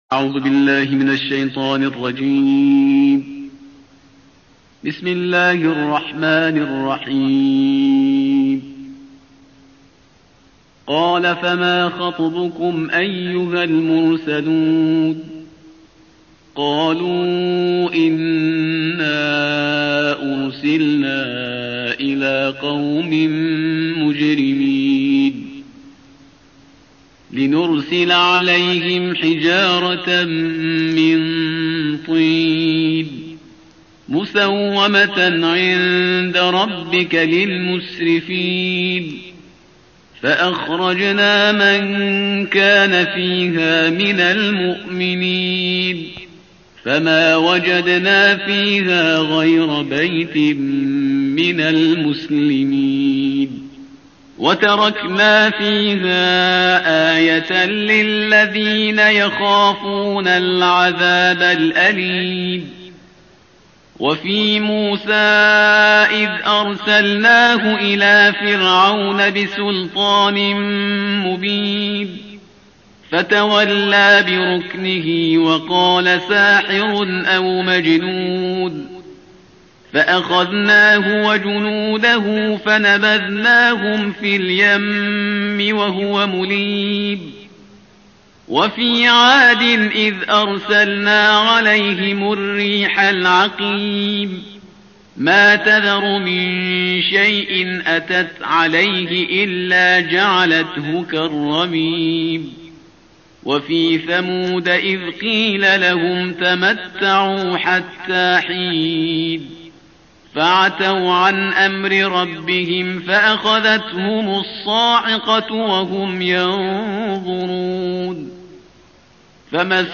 تلاوت ترتیل جزء بیست و هفتم کلام وحی با صدای استاد